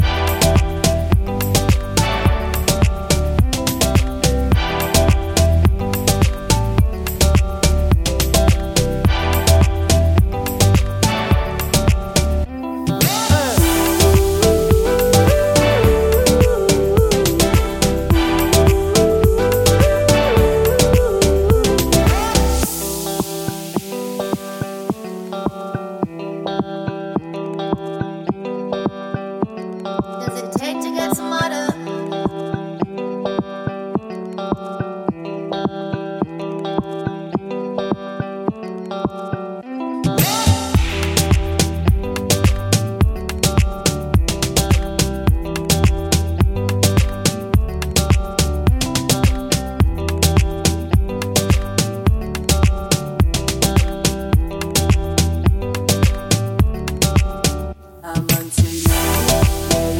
no Backing Vocals Pop (2010s) 3:22 Buy £1.50